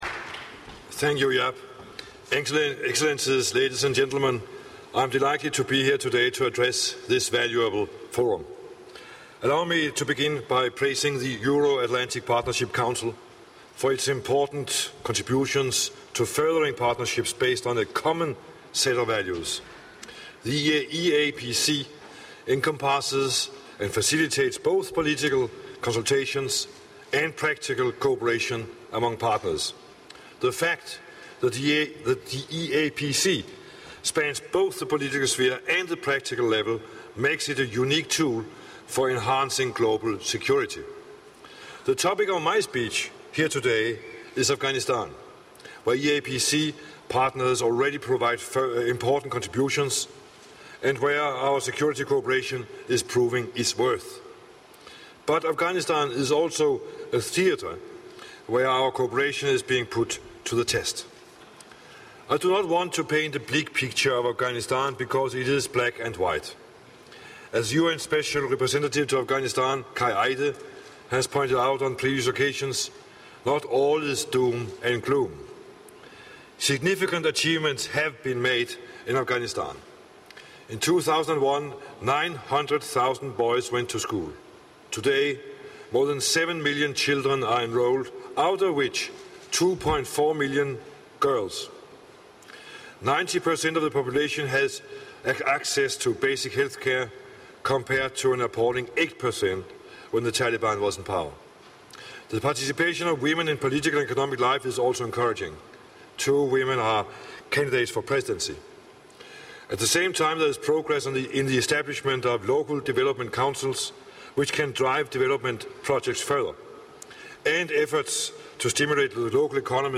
Keynote speech by the Foreign Minister of Denmark - EAPC Forum Kazakhstan - 25 June 2009